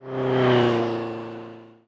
car7.wav